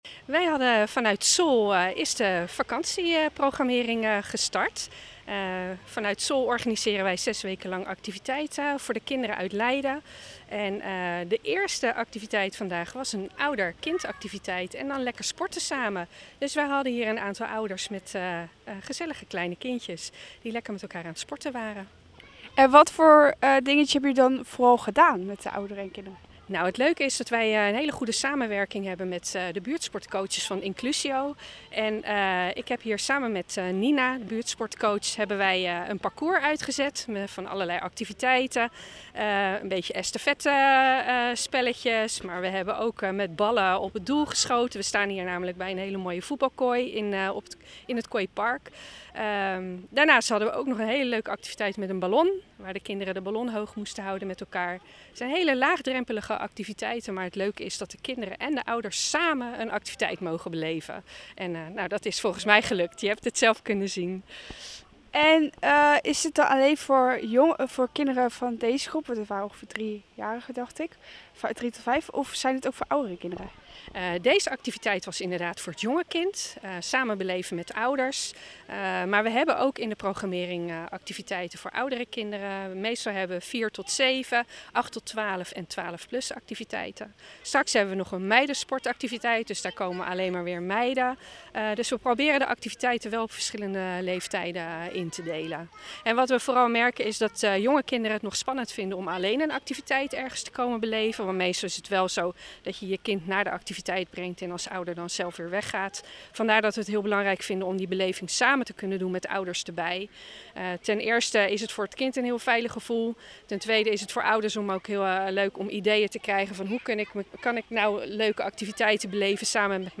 AUDIO: Verslaggever